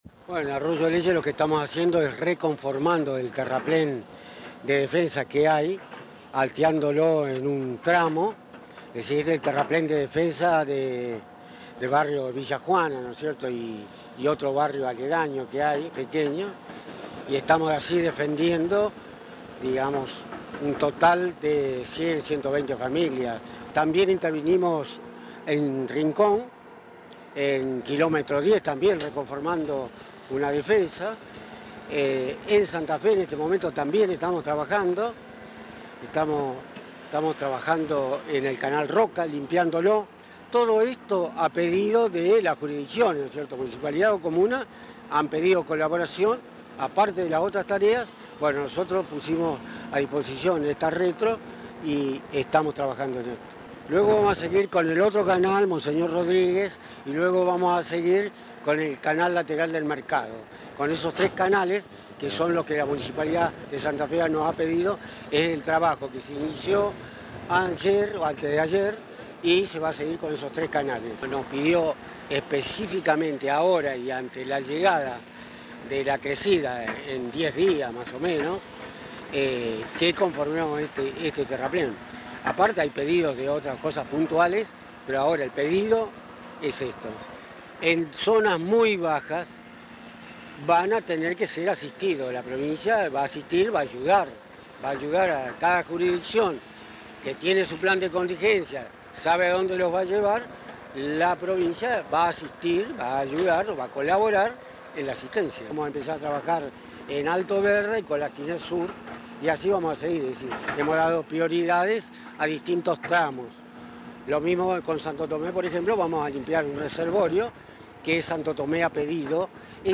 Declaraciones de Roberto Gioria